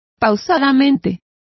Complete with pronunciation of the translation of slowly.